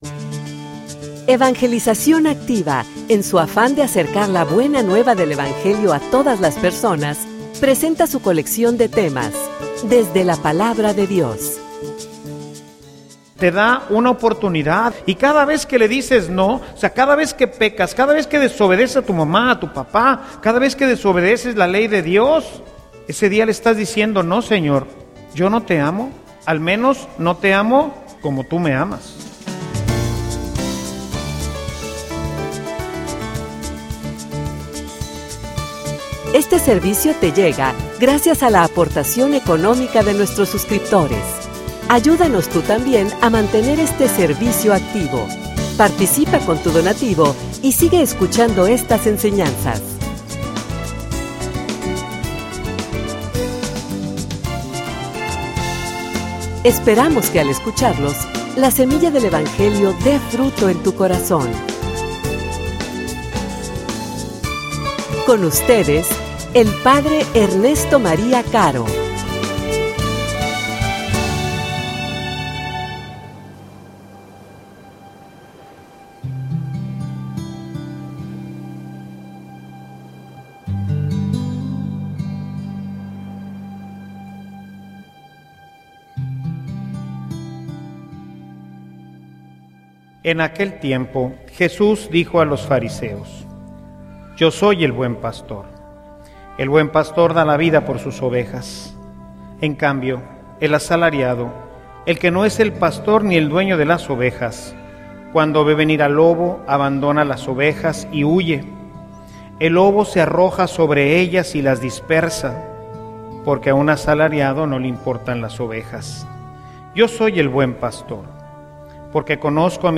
homilia_Y_tu_cuanto_amas_a_Jesus.mp3